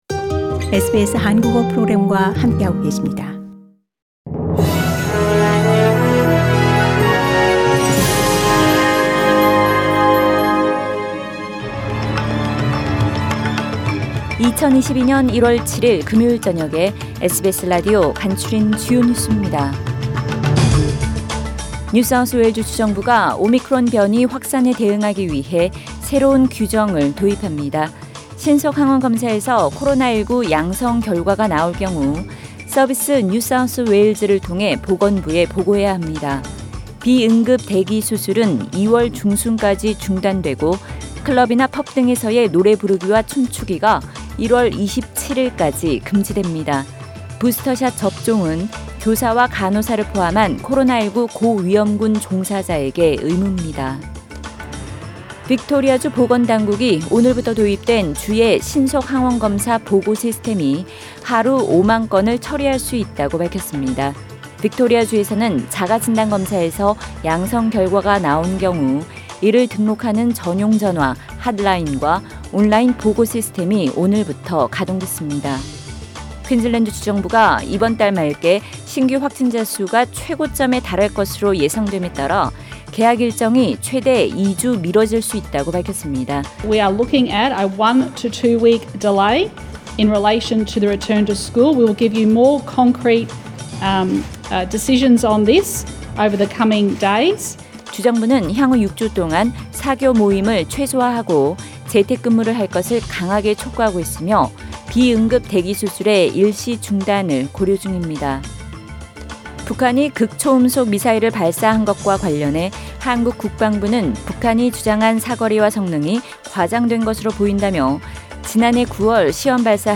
SBS News Outlines…2022년 1월 7일 저녁 주요 뉴스